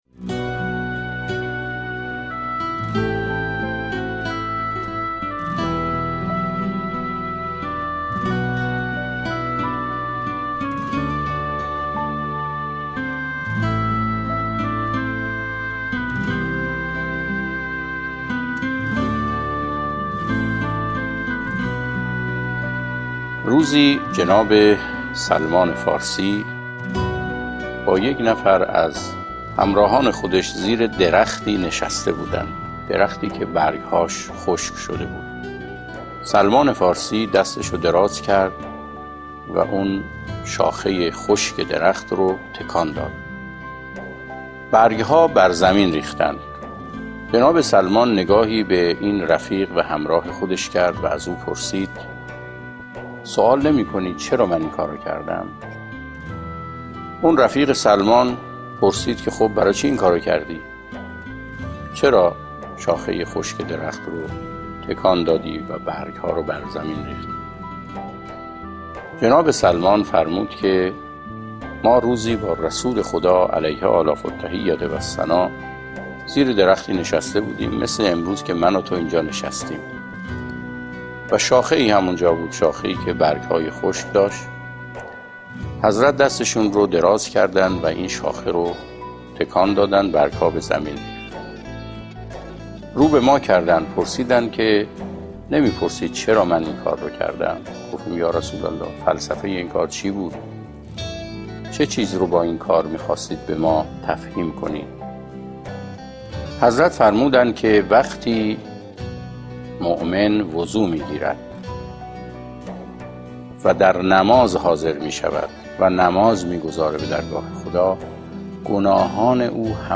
نواهنگ